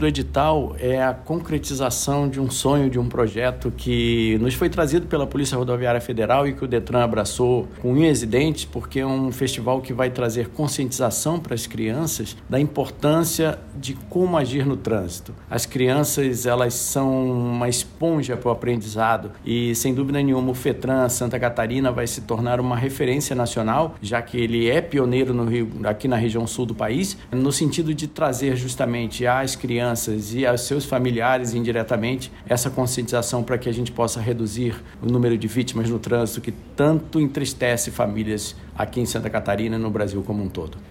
O presidente do Detran/SC, Ricardo Miranda Aversa, fala da importância de abordar a segurança no trânsito com as crianças e adolescentes: